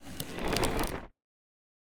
Minecraft Version Minecraft Version snapshot Latest Release | Latest Snapshot snapshot / assets / minecraft / sounds / mob / creaking / creaking_idle3.ogg Compare With Compare With Latest Release | Latest Snapshot
creaking_idle3.ogg